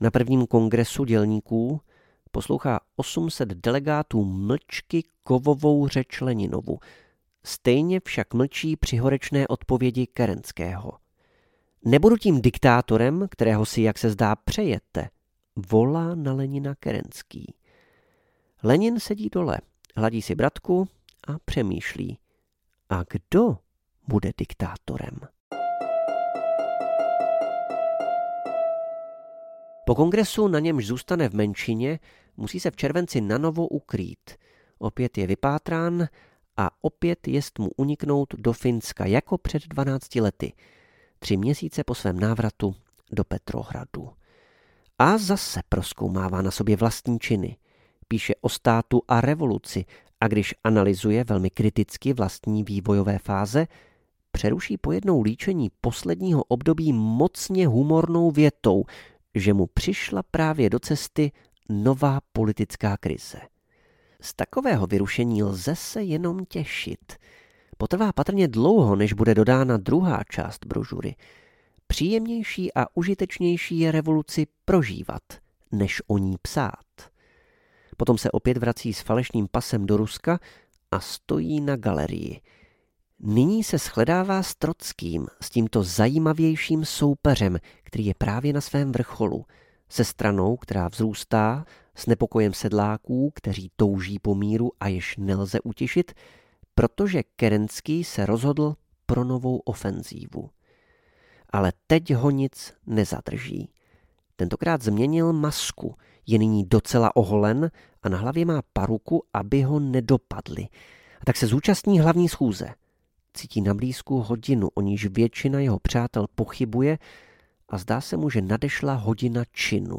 Genius a jeho charakter audiokniha
Ukázka z knihy